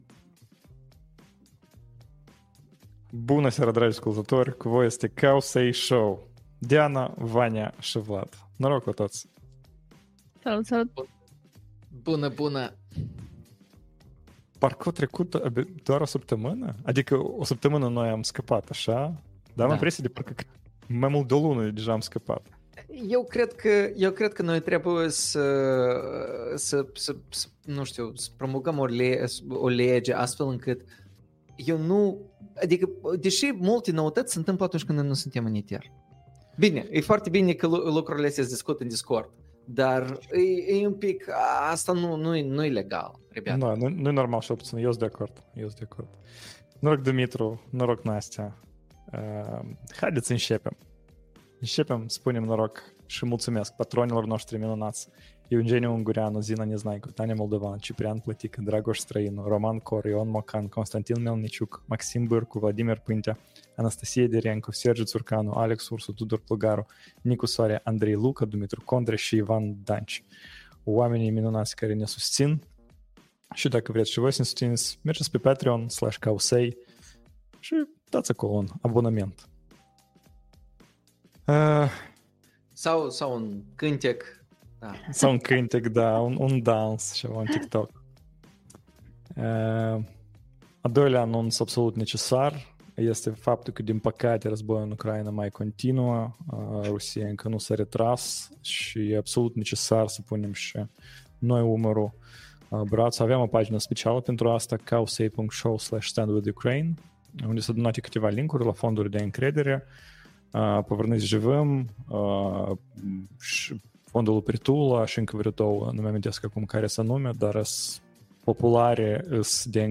February 06th, 2025 Live-ul săptămânal Cowsay Show.